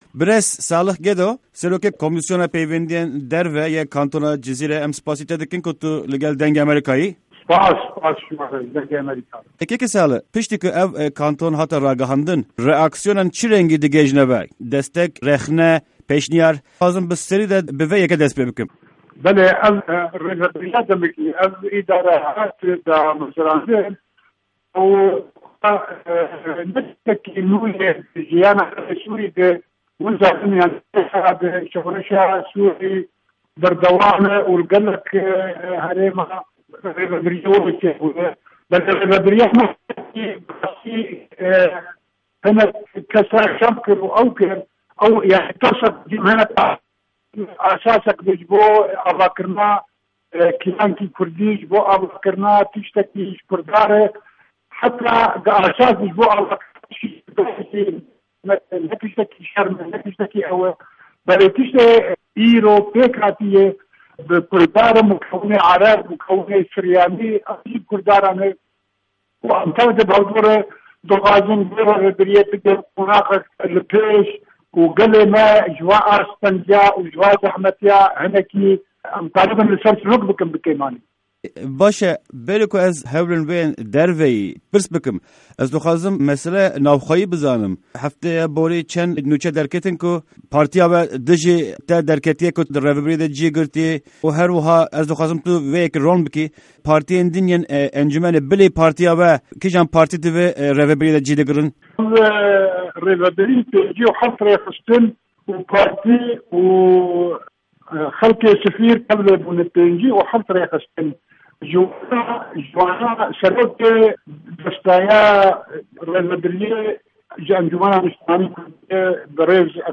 Di hevpeyvîna Dengê Amerîka de Salih Gedo, Berpirsê Komîsyona Derve yê Kantona Cizîre li ser xebatên dîplomatîk agahîyan dide.